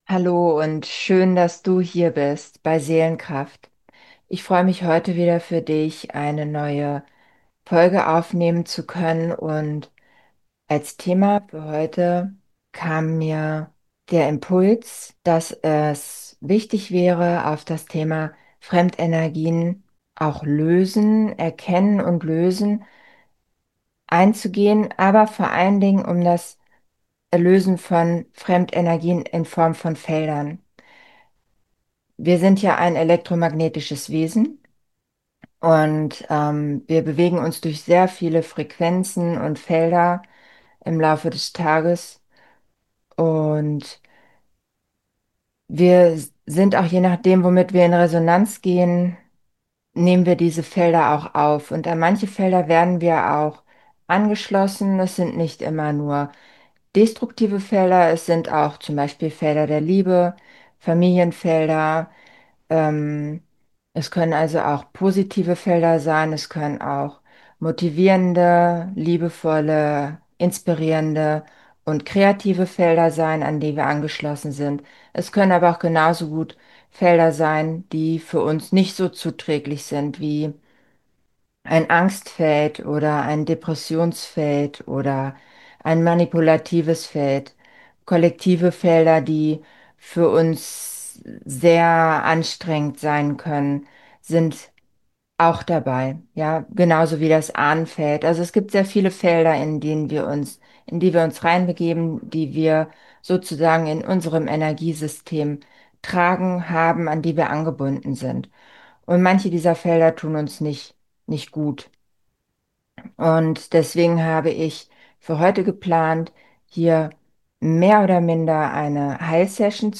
Beschreibung vor 9 Monaten Heilsession – Löse dich aus dem kollektiven Angstfeld & manipulativen Energien In dieser besonderen Folge erwartet dich keine klassische Podcast-Episode – sondern eine geführte Heilsitzung. Ich öffne einen geschützten Raum, in dem ich direkt am kollektiven Angstfeld und am manipulativen Feld arbeite.